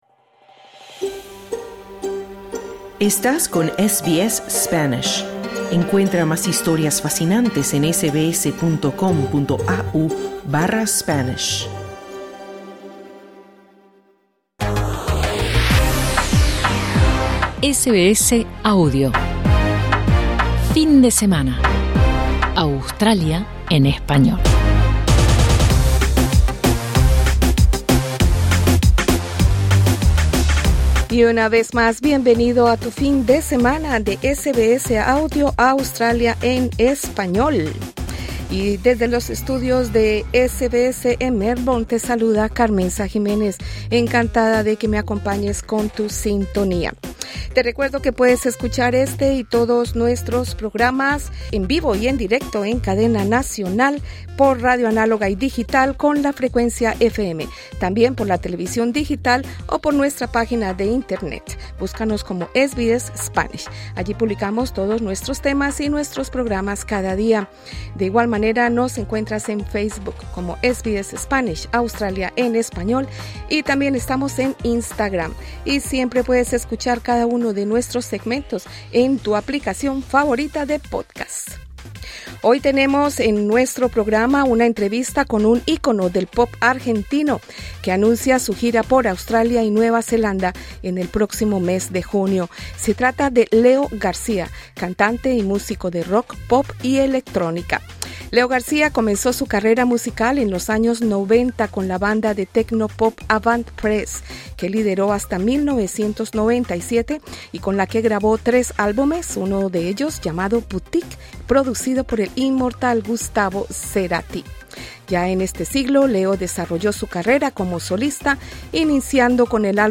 Hoy tenemos una entrevista con Leo García, un ícono del pop argentino que anuncia su gira por Australia y Nueva Zelanda en junio, bajo el nombre ‘The Modesto Tour’. También tenemos el segmento de Cibertendencias y las recomendaciones para ver SBS On Demand.